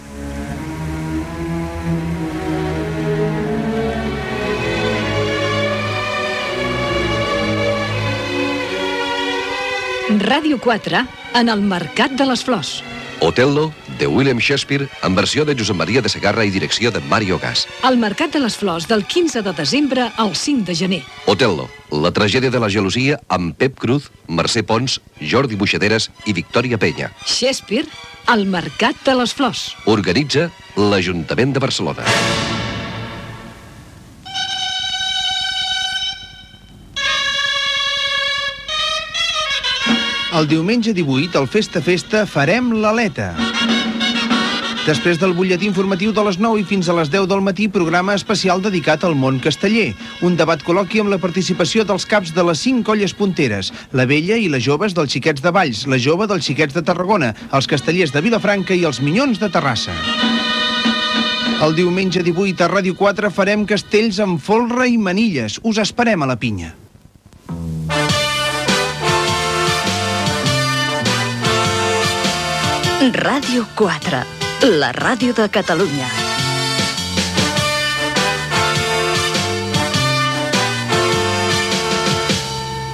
Anunci Shakespeare al Mercat de les Flors de Barcelona, promoció del programa "Fes ta festa"